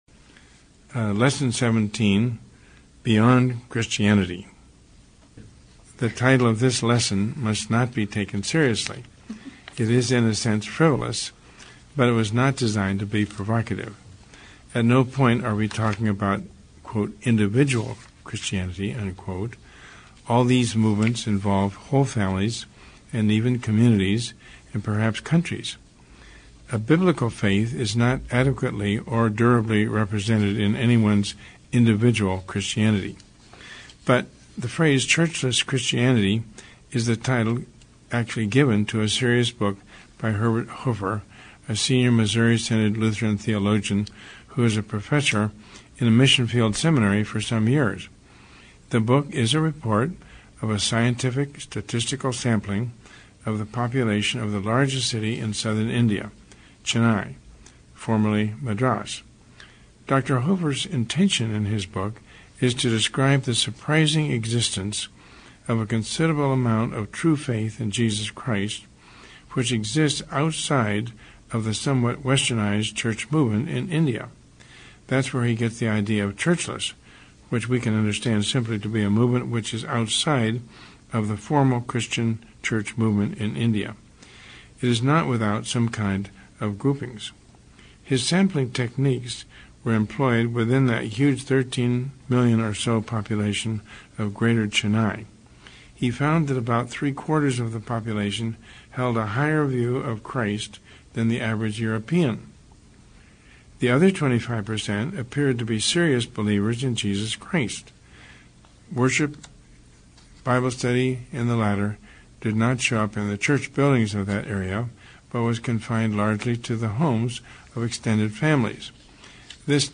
Lesson 17 Lecture: Beyond Christianity
lesson17-lecture.mp3